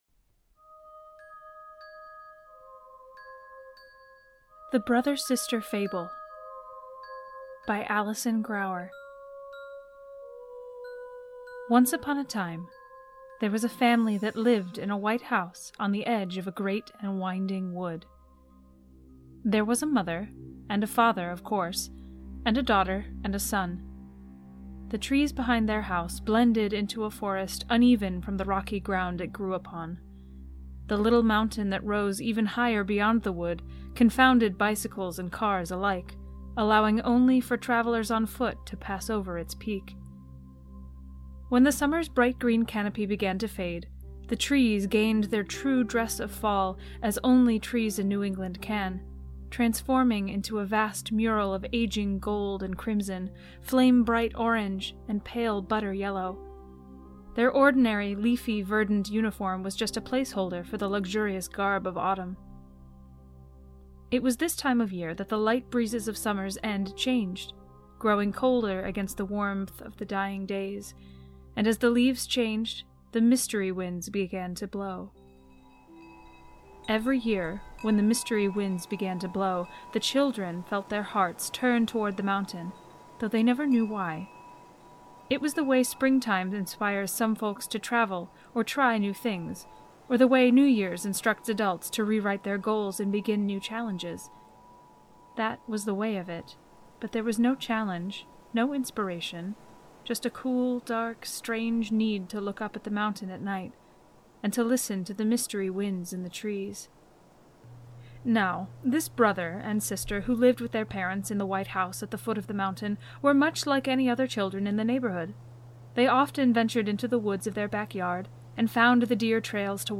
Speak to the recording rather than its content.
Some sounds provided by the Free Sound Project